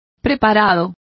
Complete with pronunciation of the translation of preparations.